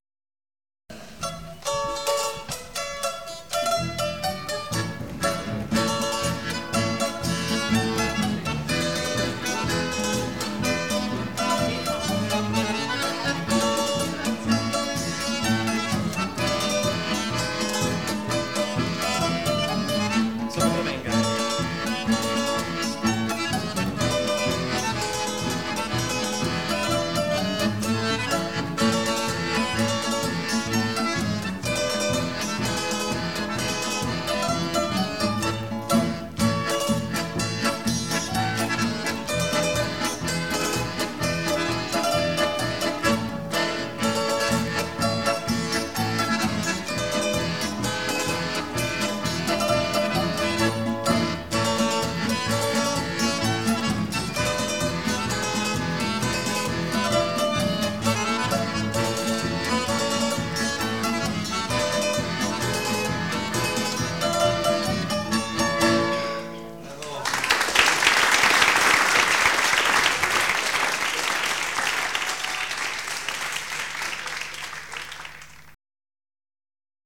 Live (1993)